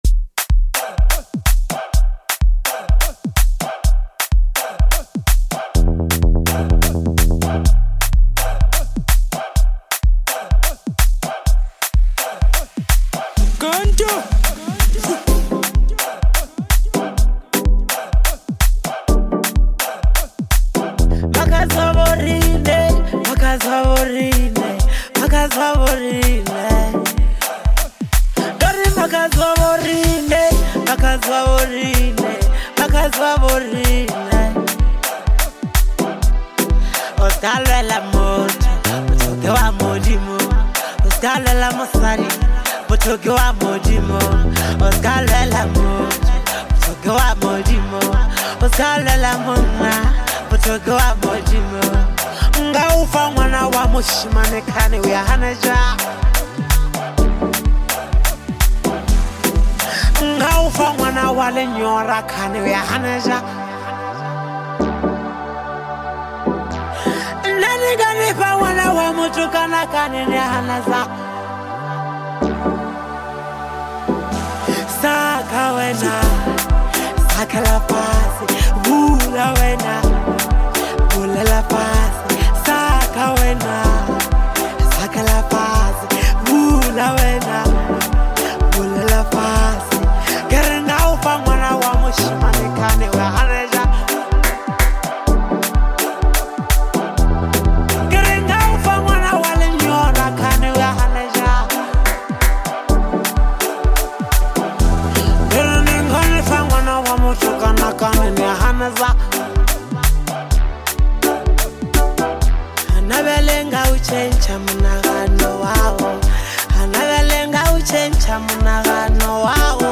lekompo project